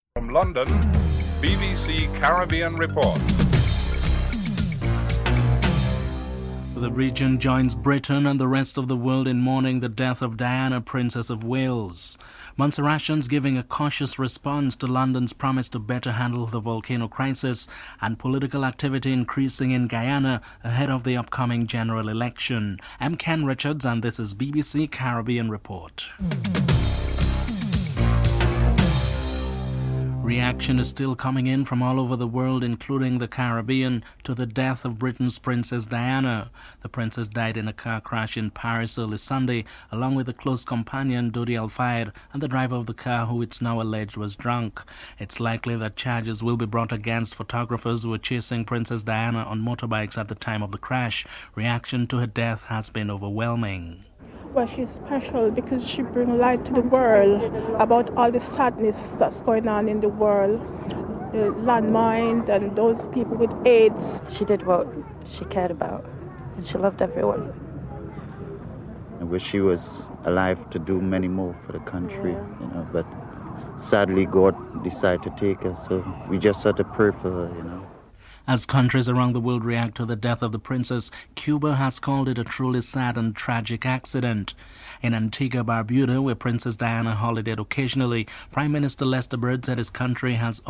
dc.description.tableofcontents3. Montserratians are giving a cautious response to London's promise to better handle the volcano crisis. British Foreign Minister George Foulkes and Chief Minister of Montserrat, David Brandt are interviewed.
Foreign Affairs Minister, George Odlum is interivewed (10:47-11:28)en_US